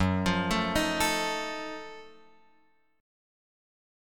F#mM13 chord {2 x 3 2 4 5} chord